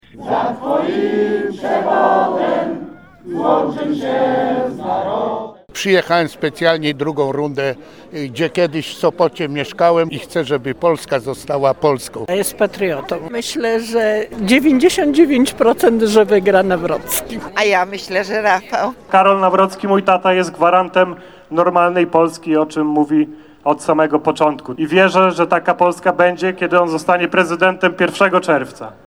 Wiec poparcia dla Karola Nawrockiego w Sopocie.
Posłuchaj relacji naszego reportera: https